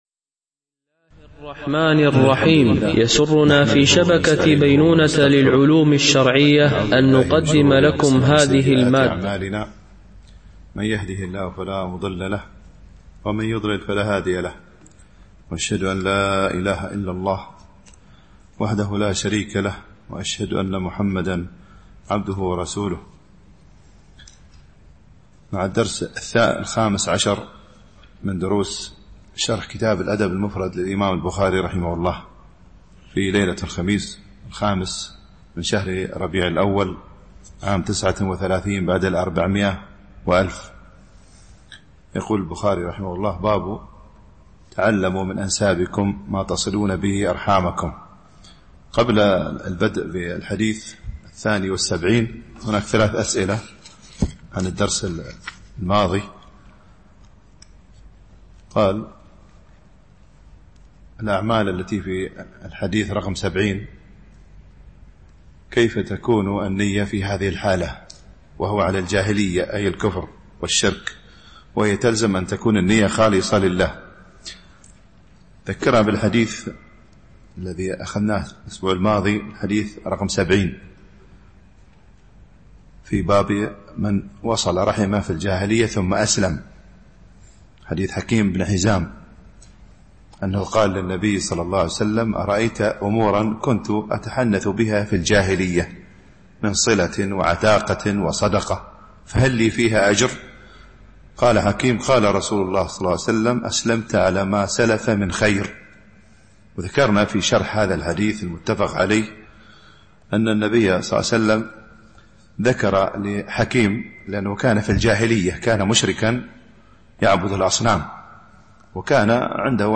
شرح الأدب المفرد للبخاري ـ الدرس 15 ( الحديث 72-73 )